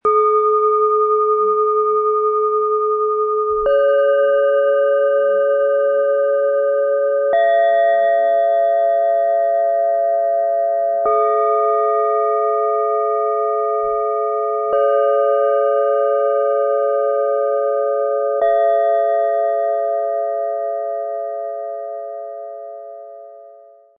Helle Freude – klar, lichtvoll, wohltuend - Set aus 3 Klangschalen, Ø 10,3 - 11,5 cm, 0,9 kg
Die Schalen klingen klar, freundlich und öffnend – ideal für Meditation, Zentrierung und tiefe Entspannung.
Ihr Klang beruhigt, gleitet ins Jetzt und trägt dich in eine entspannte Wachheit.
Ein klarer, feiner Ton öffnet dich lichtvoll und führt sanft in höhere Ebenen.
Im Sound-Player - Jetzt reinhören können Sie den Original-Klang genau dieser Schalen anhören. Erleben Sie die helle, wohltuende Schwingung dieses Sets live.
MaterialBronze